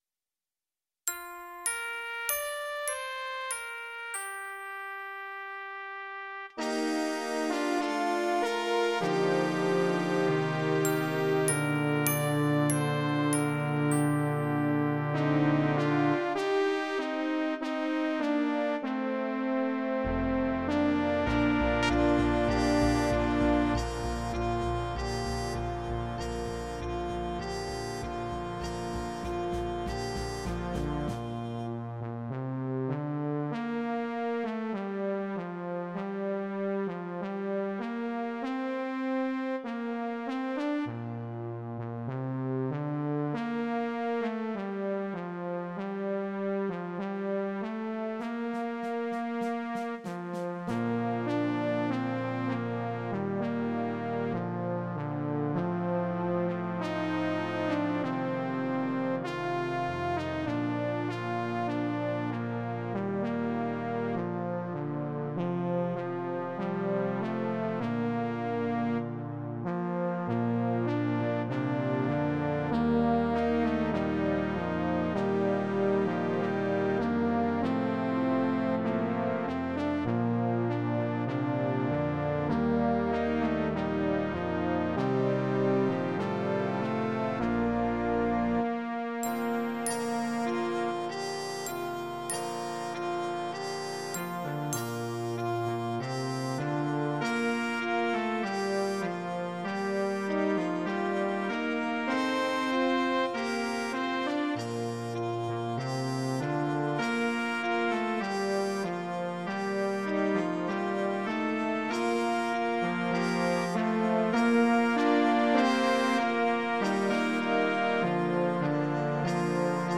a euphonium solo with brass band accompaniment